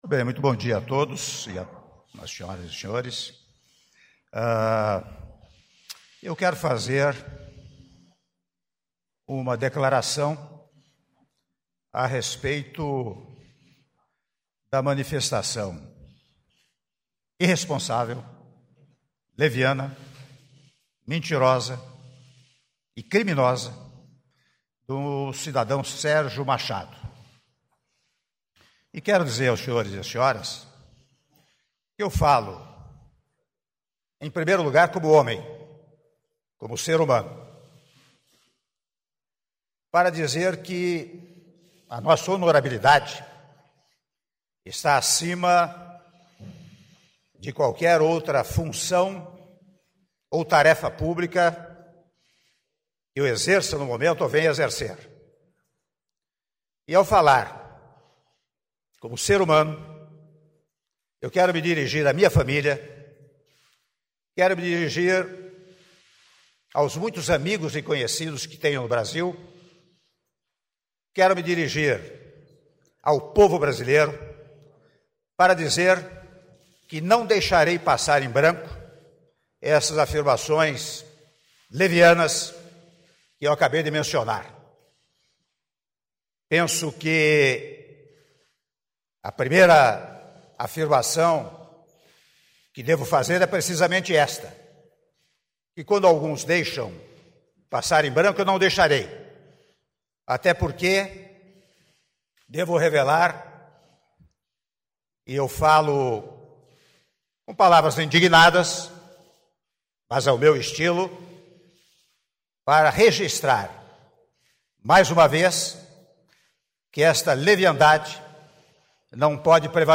Áudio do pronunciamento à imprensa do Presidente da República em exercício, Michel Temer (07min9s) - Brasília/DF
Pronunciamento